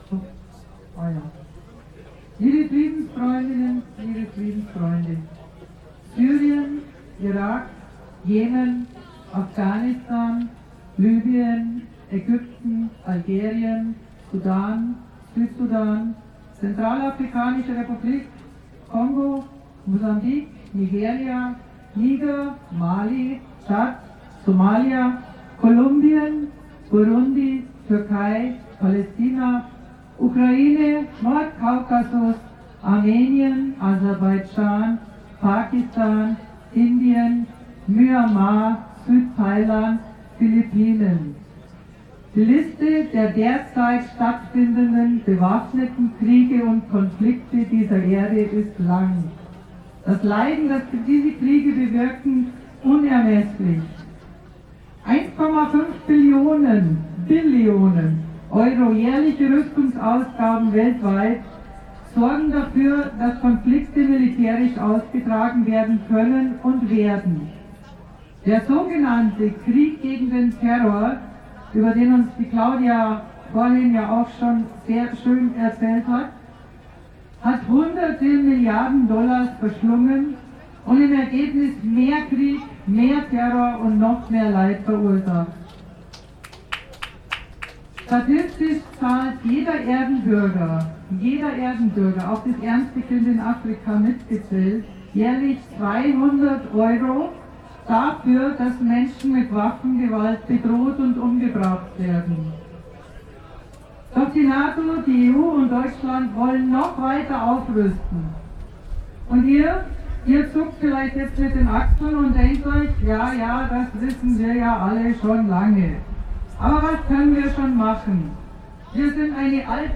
- Rede anhören